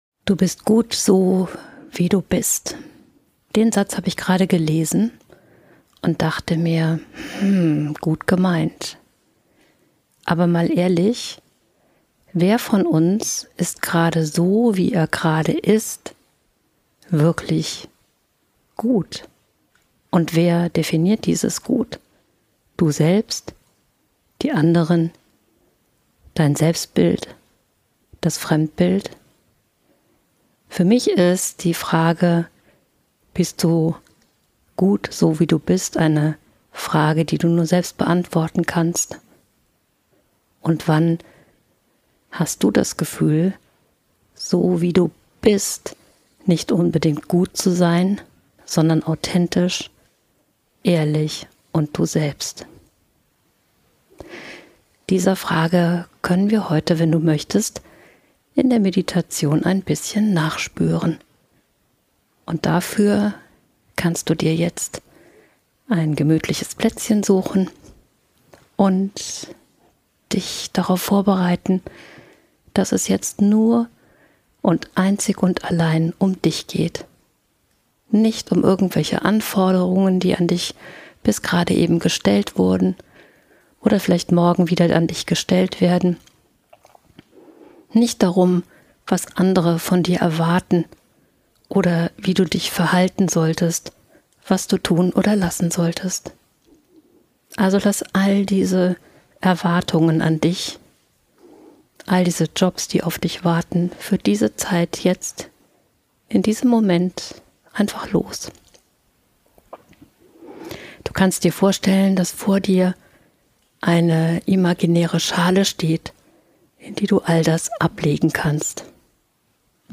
In dieser Lichtnetz-Meditation lade ich dich ein, genau das auszuprobieren. Erlebe, wie schnell du mit der besten Version deines Selbst in Kontakt kommst – und wie sich plötzlich ganz neue Räume für deine Authentizität öffnen.